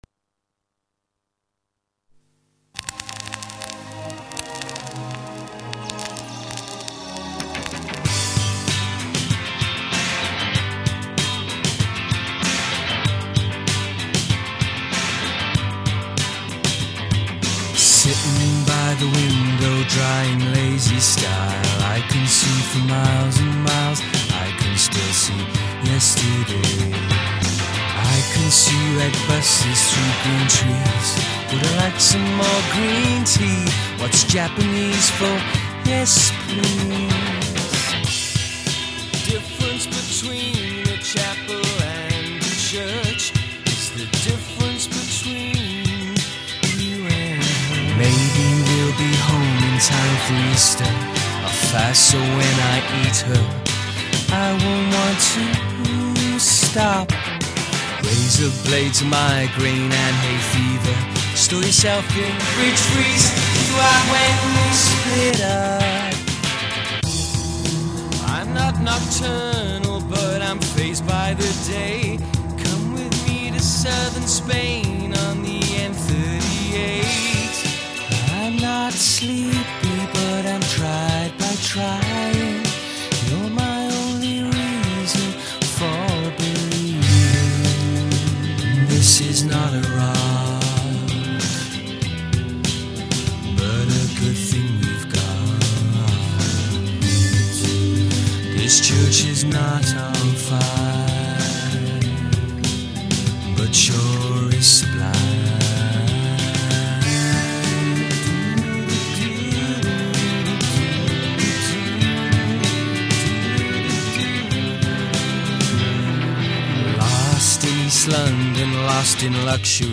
This was my take on that approach.